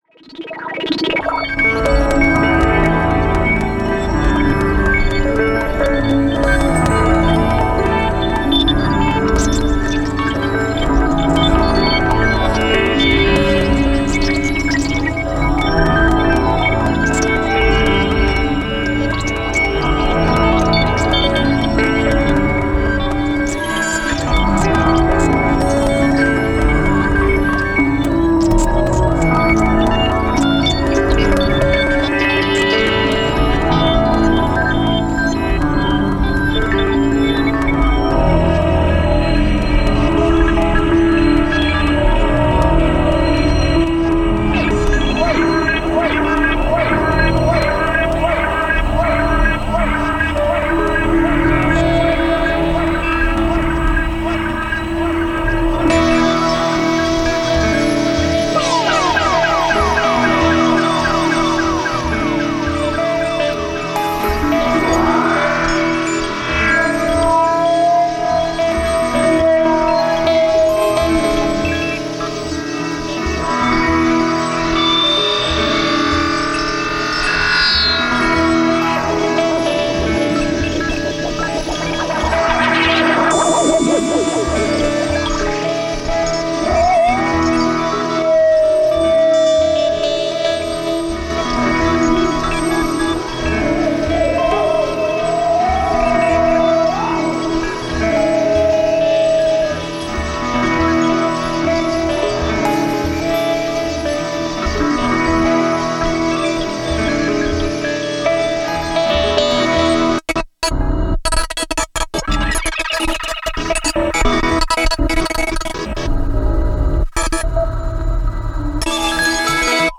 Leftfield/noise
Ambient
IDM